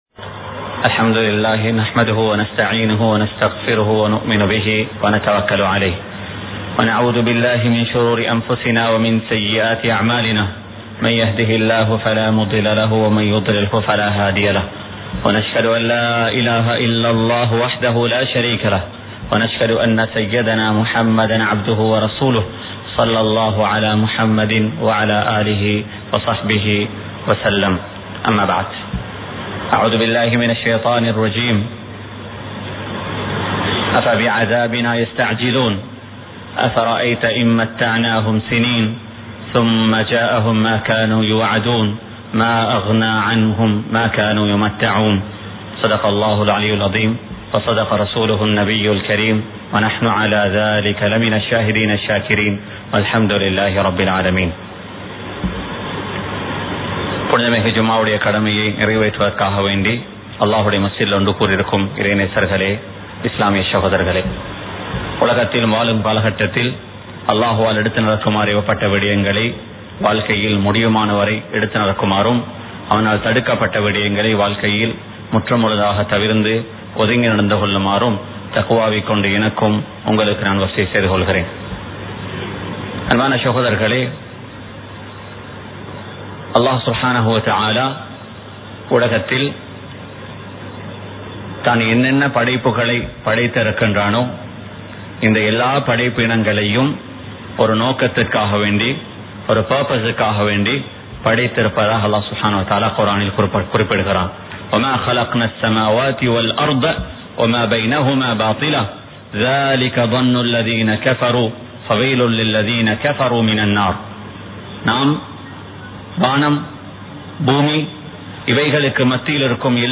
வாழ்வின் நோக்கம் | Audio Bayans | All Ceylon Muslim Youth Community | Addalaichenai
Kollupitty Jumua Masjith